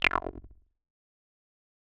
frog.wav